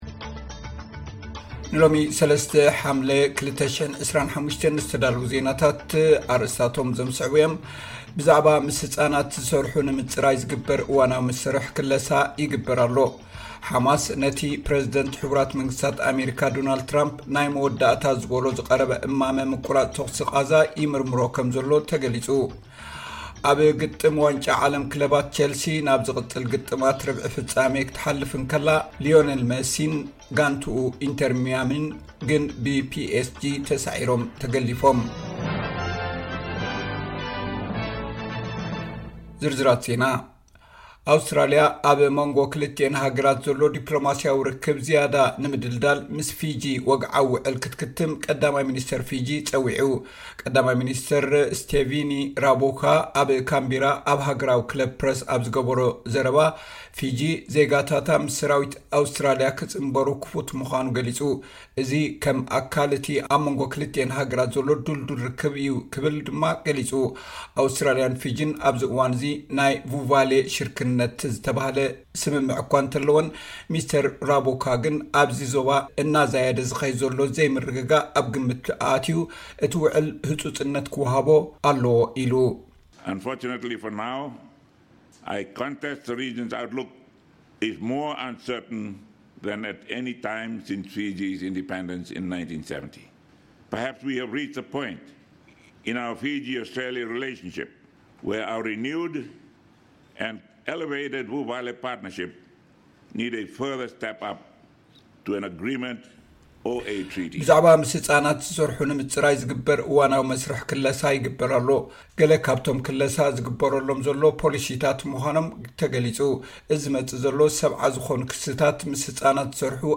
ዕለታዊ ዜና ኤስ ቢ ኤስ ትግርኛ (03 ሓምለ 2025)